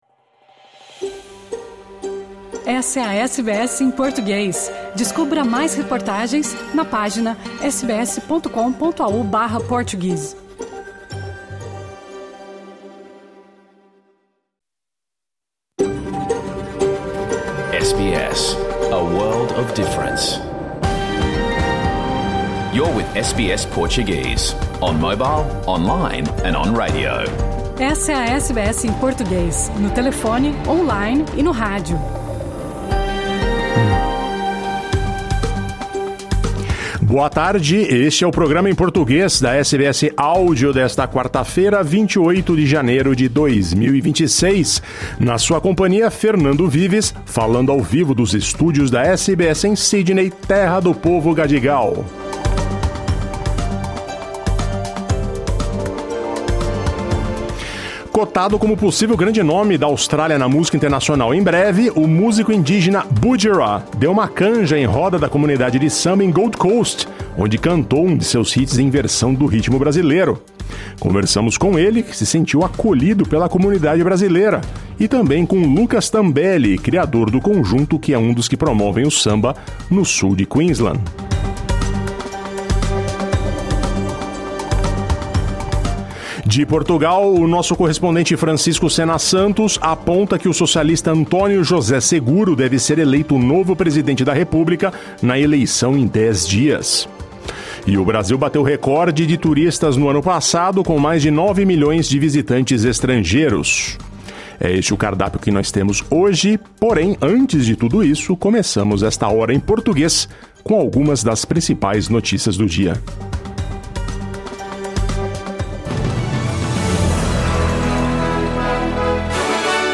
O programa em português que foi ao ar ao vivo pela SBS 2 em toda a Austrália.